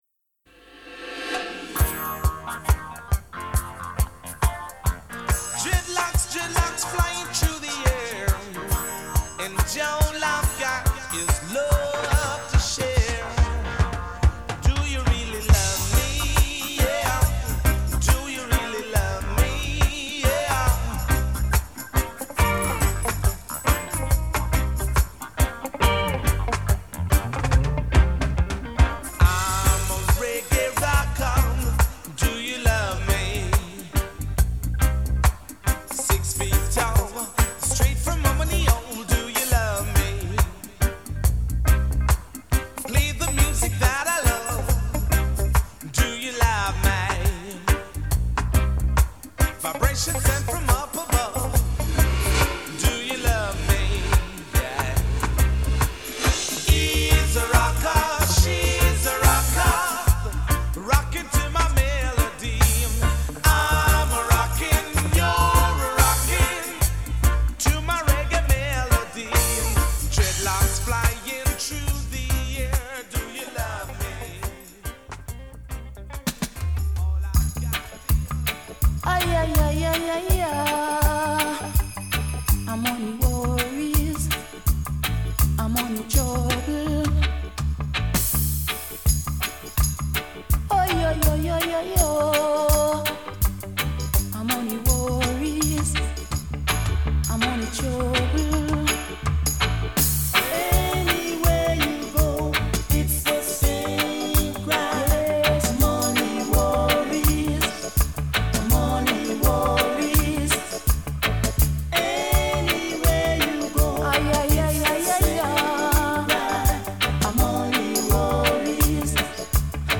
reggae
Pour accompagner ce billet, j'ai fait un petit medley .